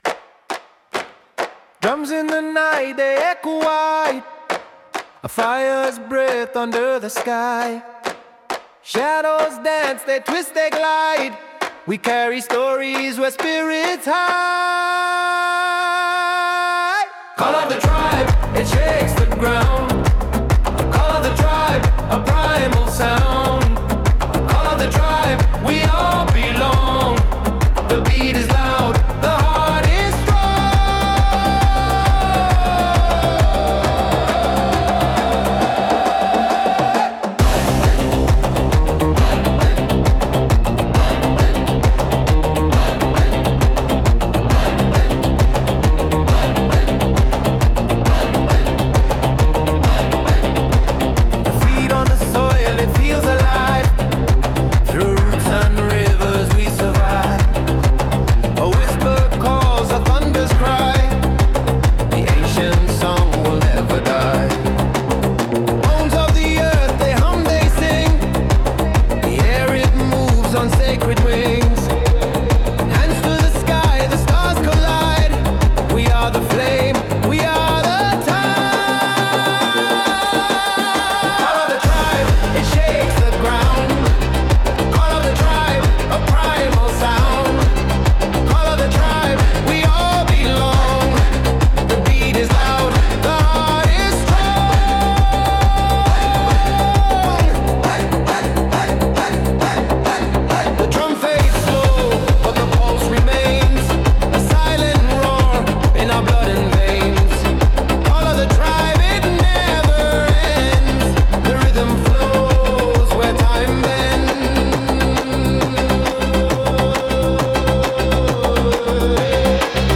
🌍 Powerful, rhythmic, and deeply primal.
blends driving percussion and tribal chants,
evoking unity, strength, and the heartbeat of the earth.
大地を揺るがすようなビートと、魂を呼び覚ますようなコーラスが響き渡るトライバルチューン。
太鼓のリズムが部族の絆とエネルギーを感じさせ、ステージを熱く包み込みます。